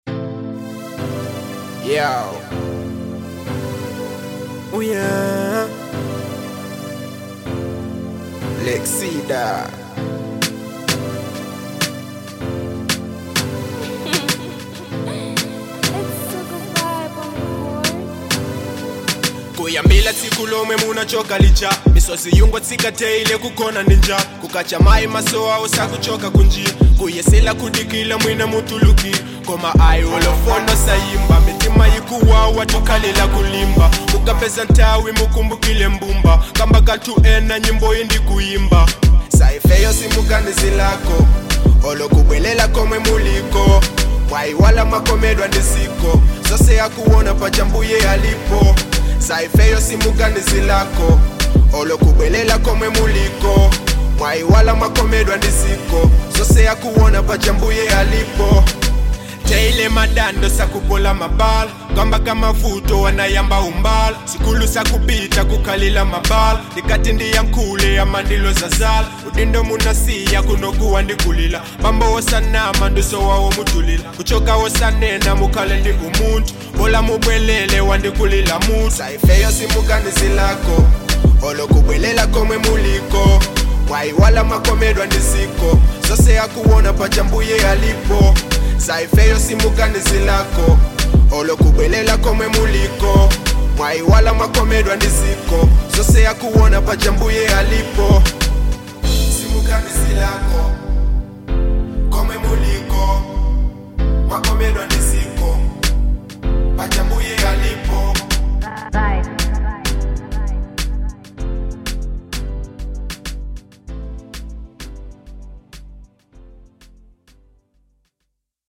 Genre : Afro Dancehall
emotional and melodic track
smooth trap production
creating a soulful vibe.